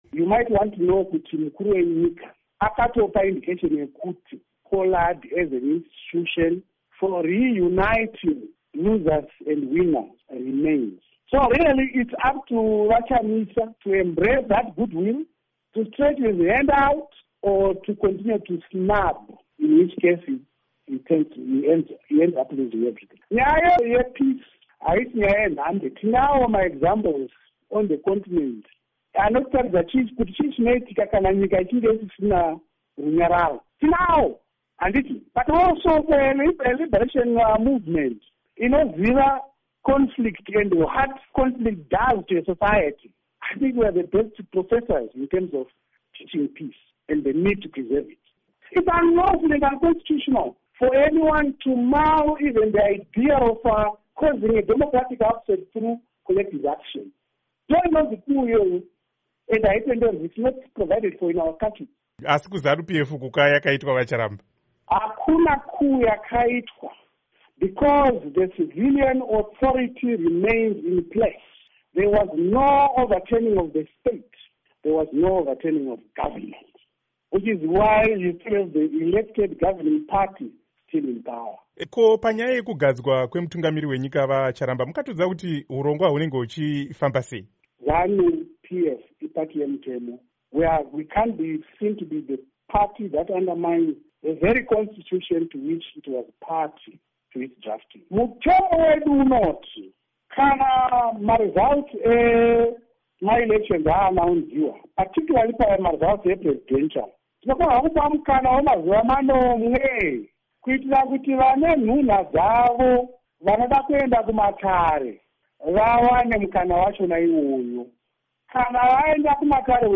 Hurukuro naVaGeorge Charamba